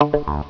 EnterRoom.wav